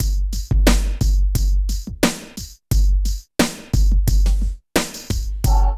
64 DRUM LP-L.wav